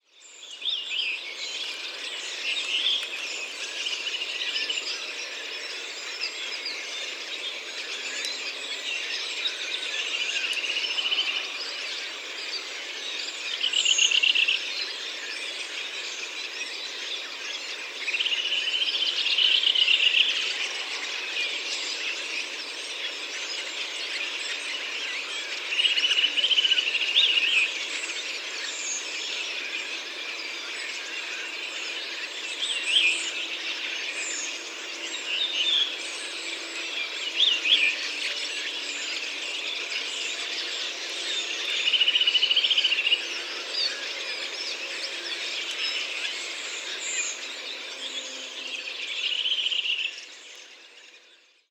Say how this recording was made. north-western Saxony, Germany, Telinga parabolic reflector